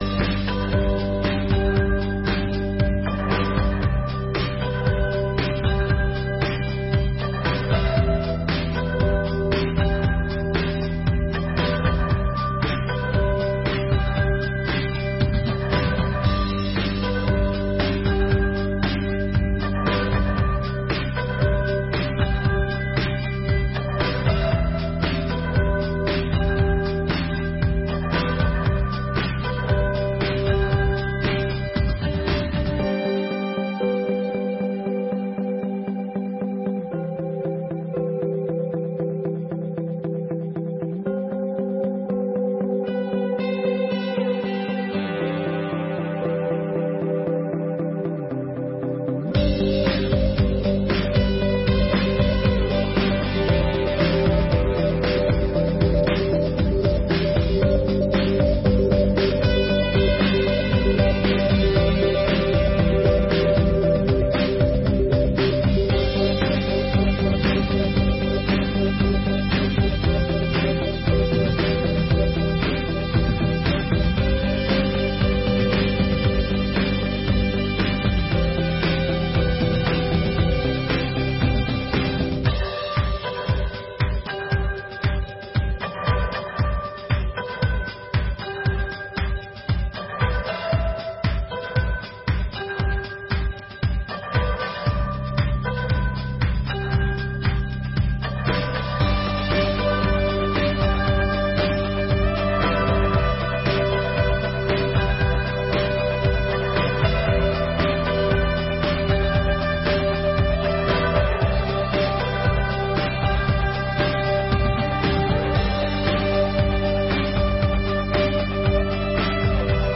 Audiências Públicas de 2022